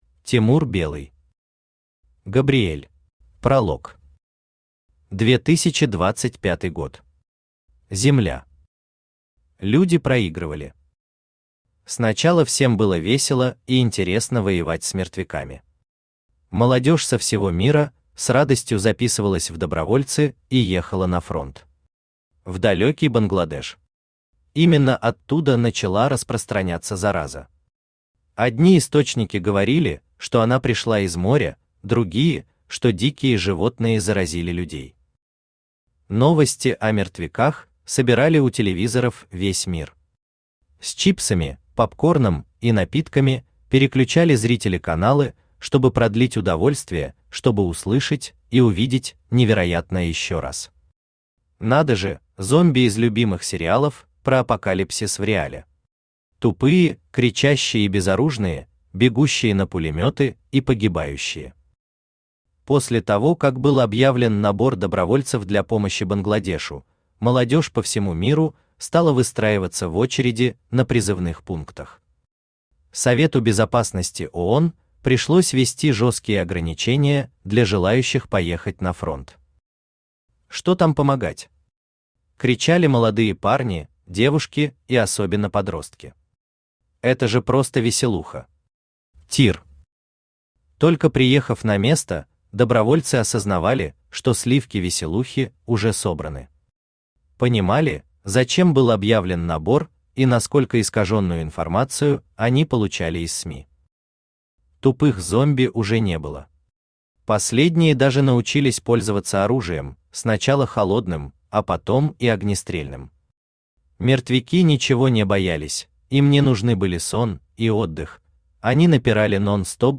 Aудиокнига Габриэль Автор Тимур Белый Читает аудиокнигу Авточтец ЛитРес.